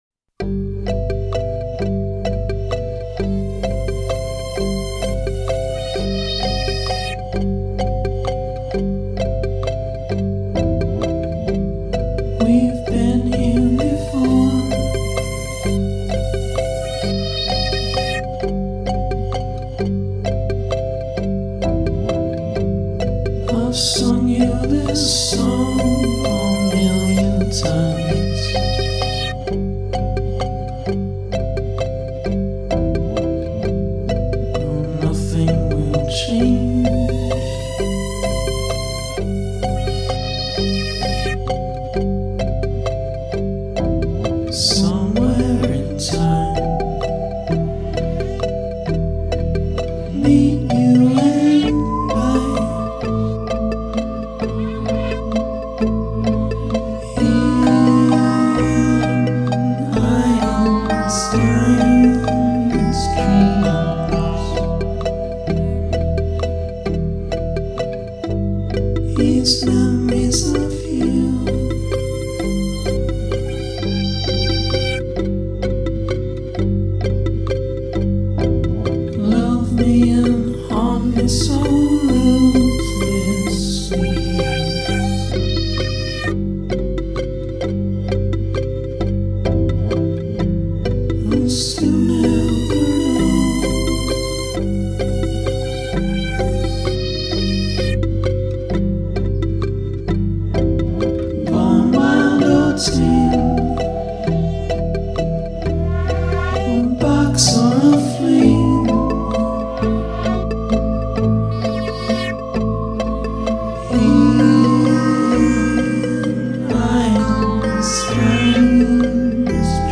synth bass